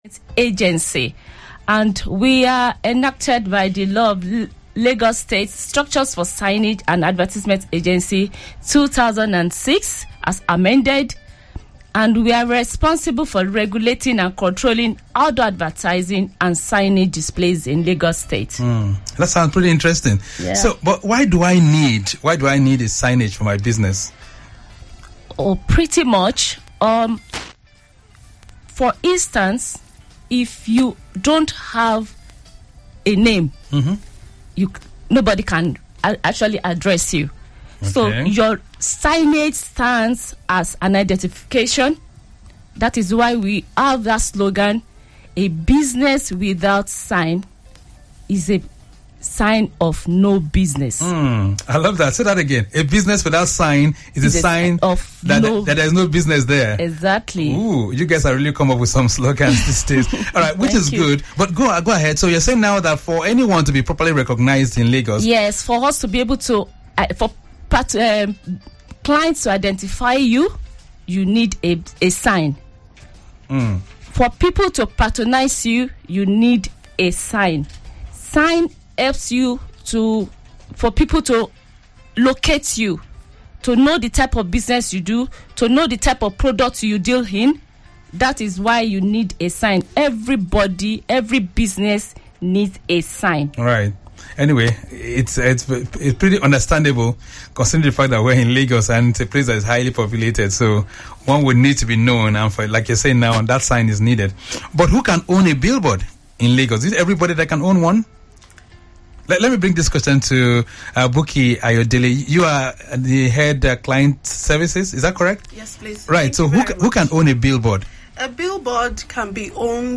LASAA AWARENESS INTERVIEW ON TRAFFIC RADIO 96.1 | Lagos State Signage & Advertisement Agency (LASAA)